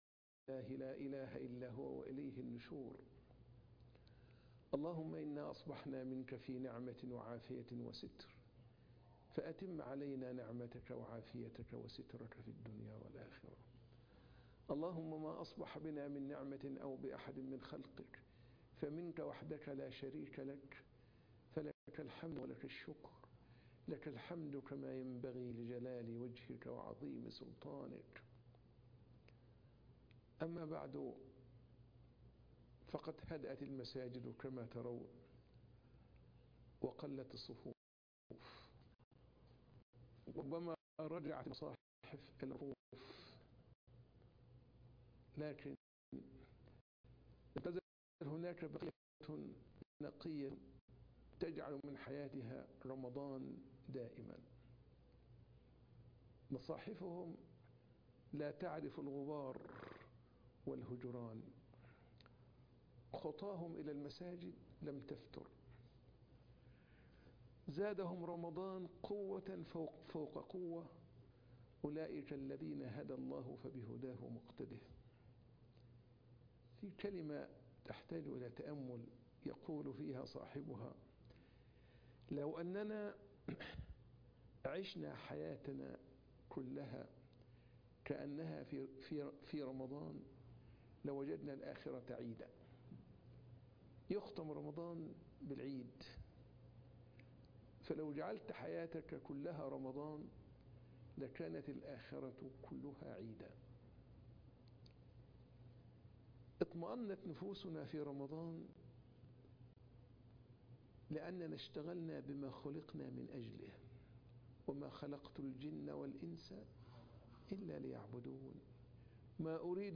عنوان المادة متى تصبح الآخرة عيدا (درس ما بعد الفجر)